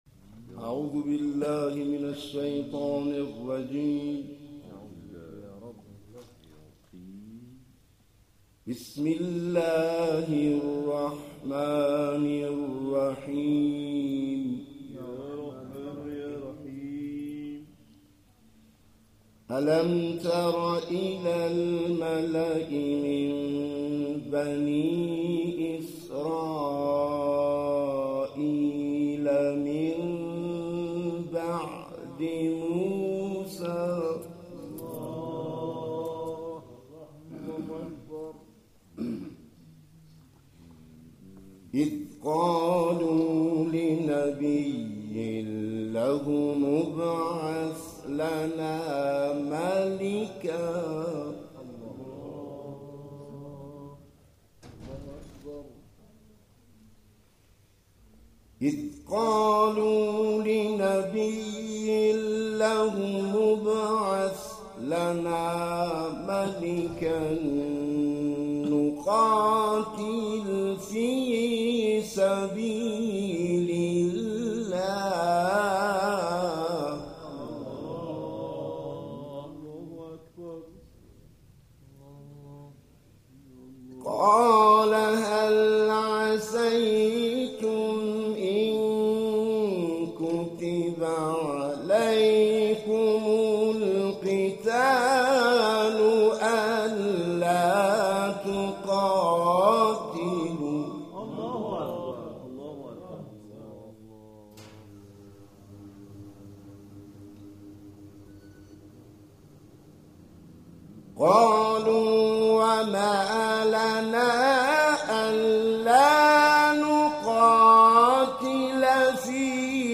در این جلسه صمیمی، اعضای جلسه به تلاوت آیاتی از کلام الله مجید پرداختند
تلاوت